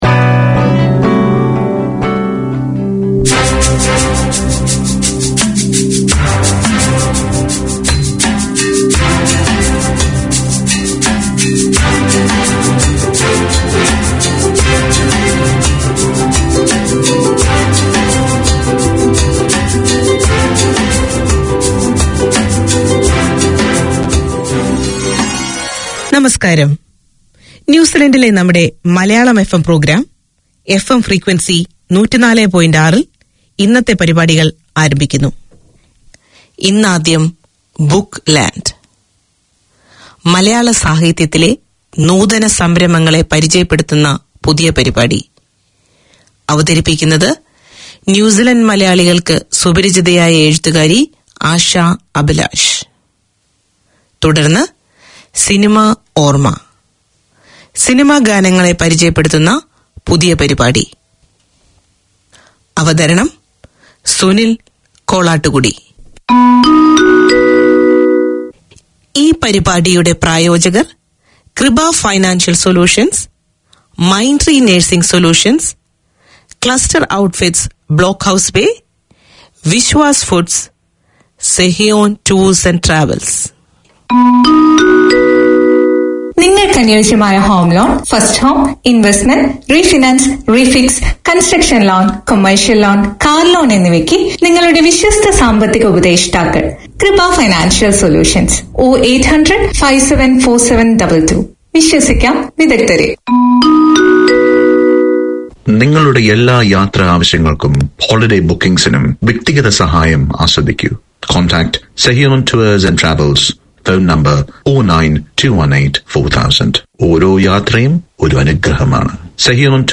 Radio made by over 100 Aucklanders addressing the diverse cultures and interests in 35 languages.
A celebration of Malayalam language, literature, music and culture; Malayalam FM presents three weekly programmes. Hear dramas and stories based on Malayalam songs on Fridays, film and music on Saturdays, and enjoy a talk-based show featuring discussions and interviews on the Sunday edition.